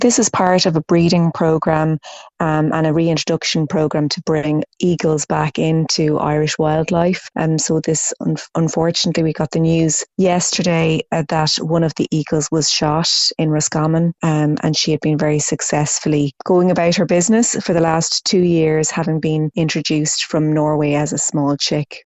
Green Party Senator Pauline O’Reilly is asking anyone who may have information to come forward………